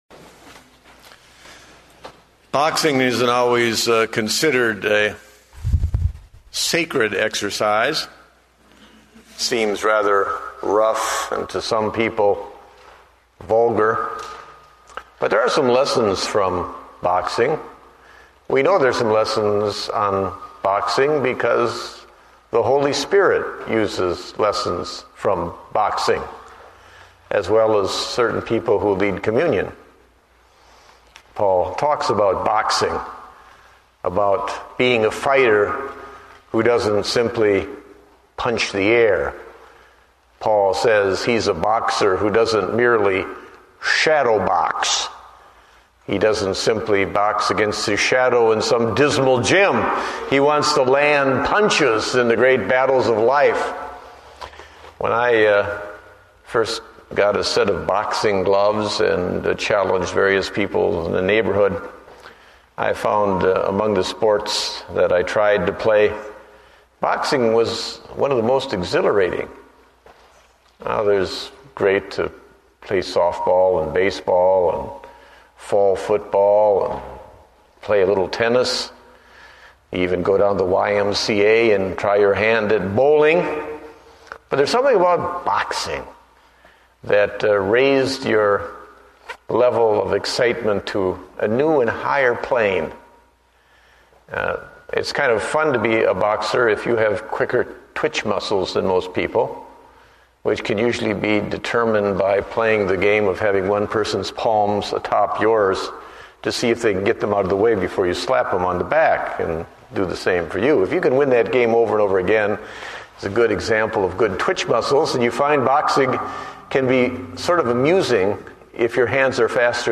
Date: May 3, 2009 (Evening Service)